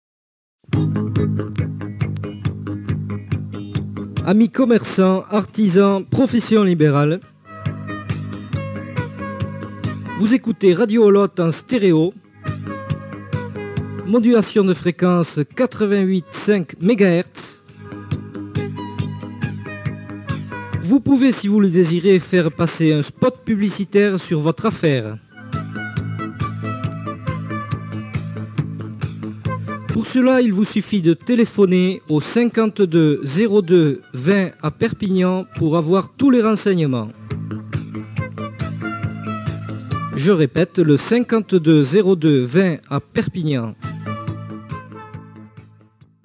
Identificació, freqüència i telèfon de contacte a Perpinyà
Banda FM